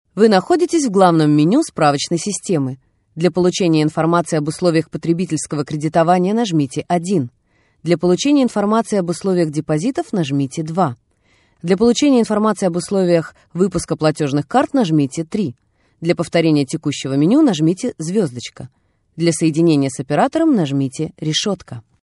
Russian – female – AK Studio